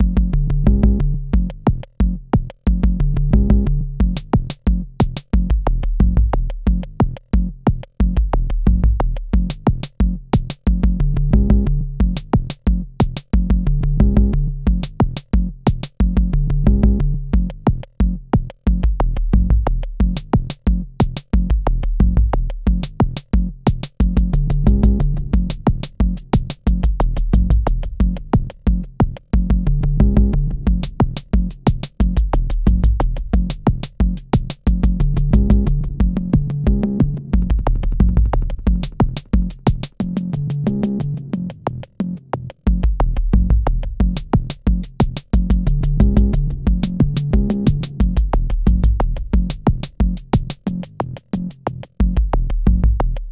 Bucle de Ambient Techno
melodía
repetitivo
sintetizador
Sonidos: Música